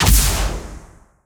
Rocket Launcher
GUNArtl_Rocket Launcher Fire_01_SFRMS_SCIWPNS.wav